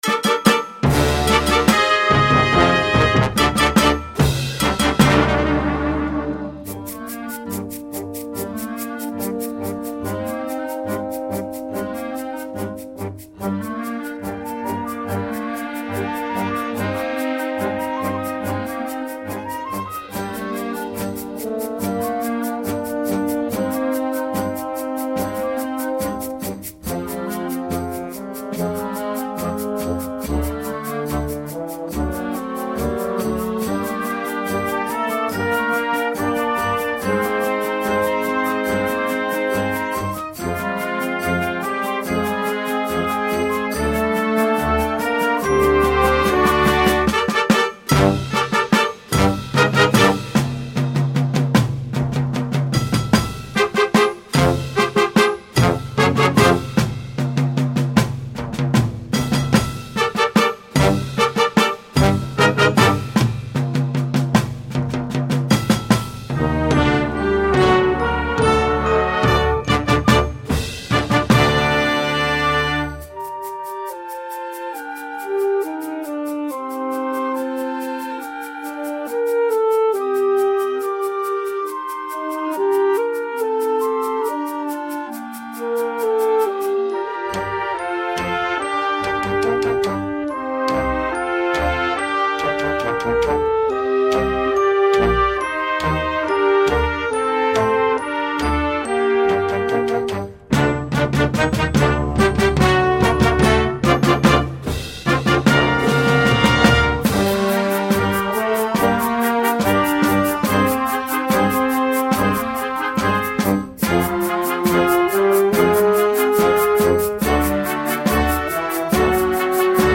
Gattung: Blasmusik für Jugendkapelle
Besetzung: Blasorchester
using bold and aggressive themes